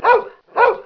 Dog Barking Download
Dogbark.mp3